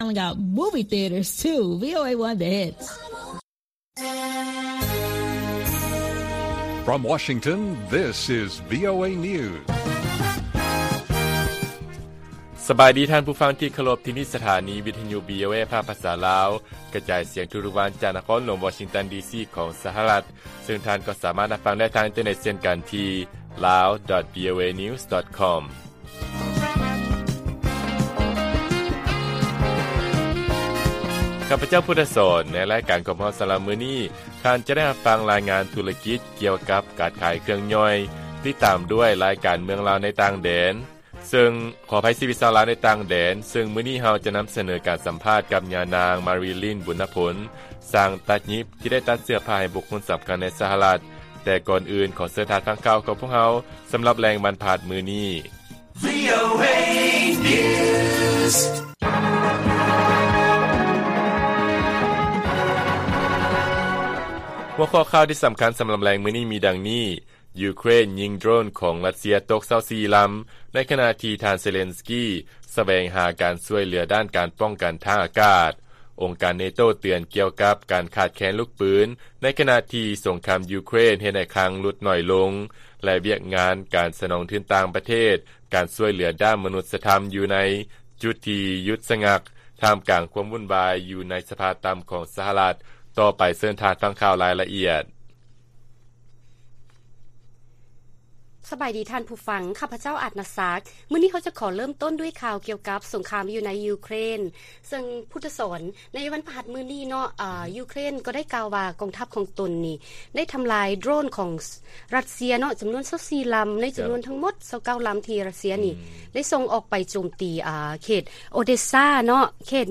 ລາຍການກະຈາຍສຽງຂອງວີໂອເອ ລາວ: ຢູເຄຣນ ຍິງໂດຣນຂອງຣັດເຊຍ ຕົກ 24 ລຳ ໃນຂະນະທີ່ ທ່ານເຊເລັນສກີ ສະແຫວງຫາການຊ່ວຍເຫຼືອ ດ້ານການປ້ອງກັນທາງອາກາດ